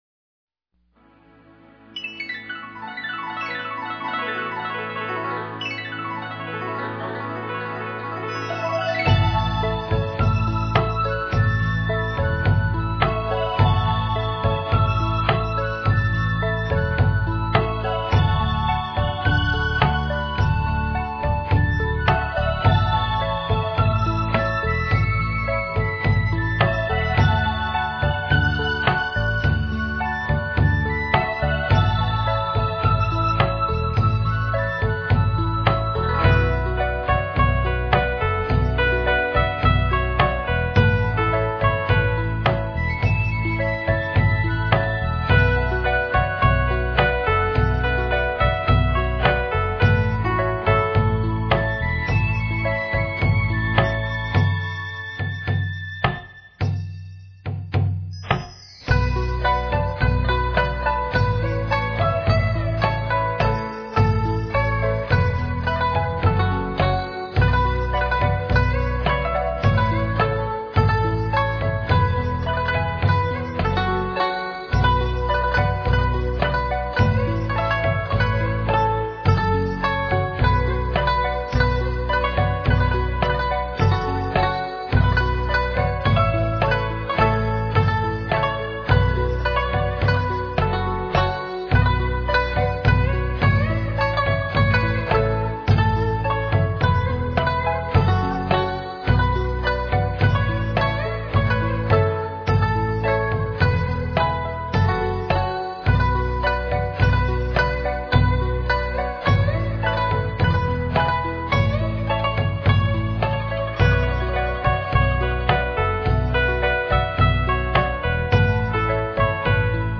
古筝佛赞选曲